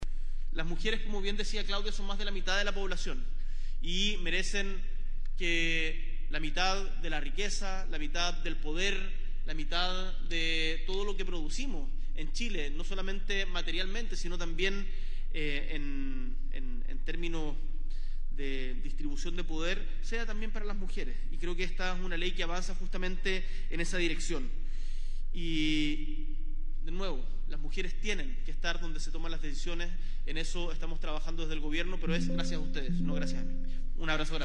Este lunes el presidente Gabriel Boric encabezó la promulgación de la “Ley Más Mujeres en Directorios”, iniciativa que tiene como objetivo aumentar la participación de las mujeres en las mesas directivas de las sociedades anónimas, abiertas y especiales fiscalizadas por la Comisión del Mercado Financiero (CMF).